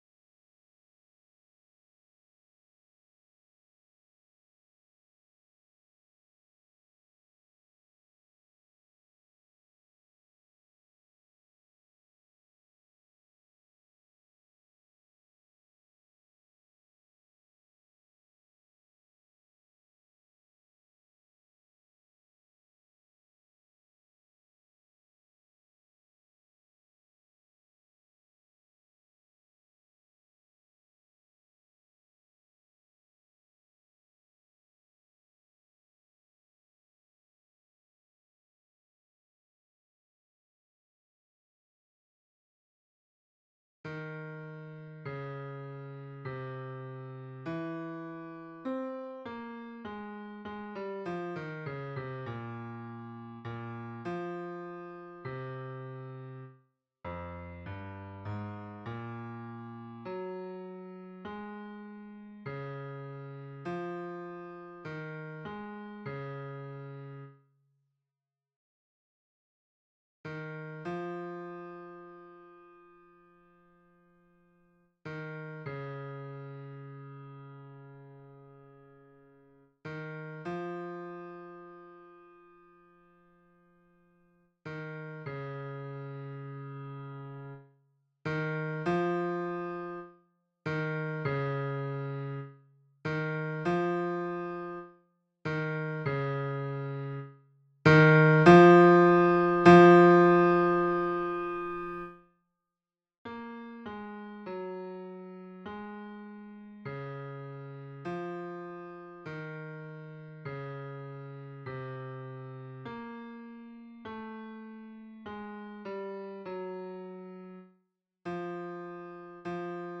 Baryton (version piano)